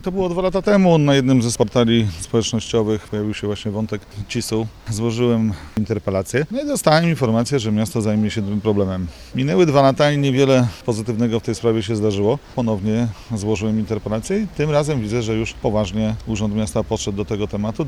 Już 2 lata temu o potrzebie zajęcia się tym drzewem mówił na sesji rady miasta polityk PiS-u Jacek Budziński: